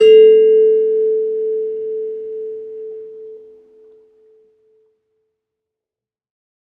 kalimba1_circleskin-A3-ff.wav